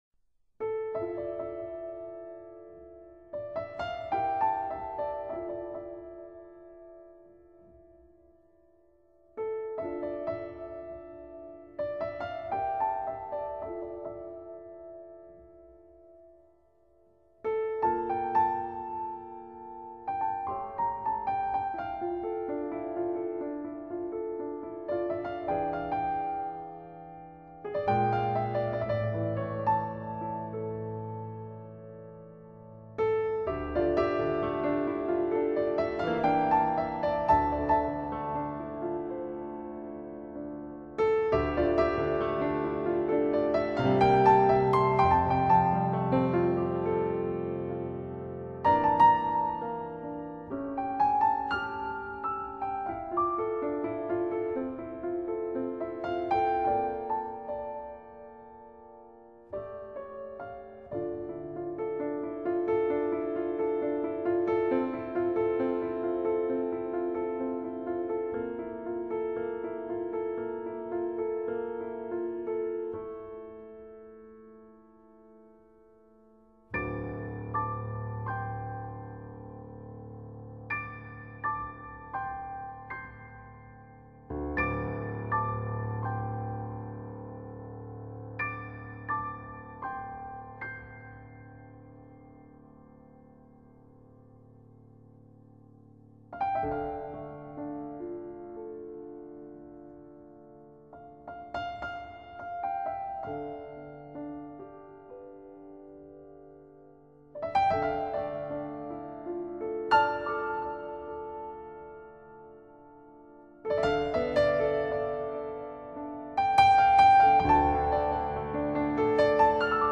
身为爵士音乐家，她的音乐由于具有鲜明的东方特色，女性气质和神秘感，很容易就脱颖而出，
一首极其优美的钢琴独奏，改编自更早以前她自己的风格迥异的爵士曲。